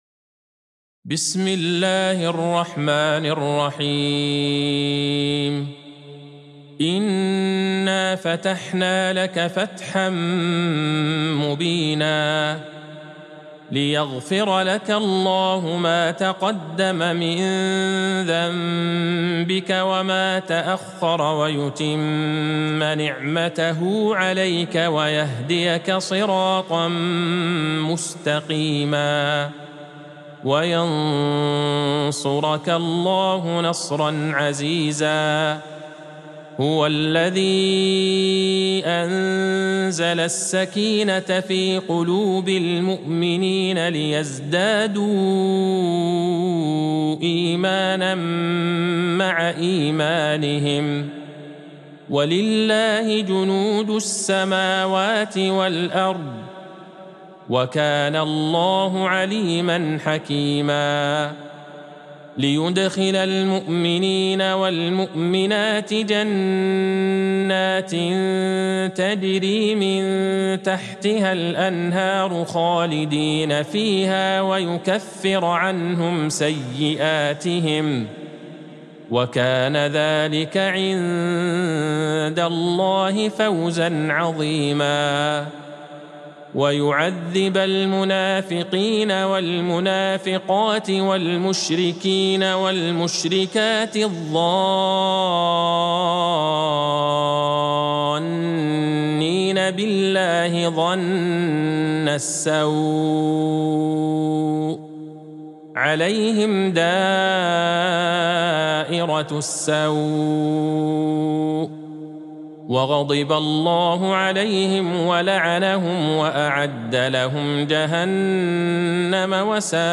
سورة الفتح Surat Al-Fath | مصحف المقارئ القرآنية > الختمة المرتلة ( مصحف المقارئ القرآنية) للشيخ عبدالله البعيجان > المصحف - تلاوات الحرمين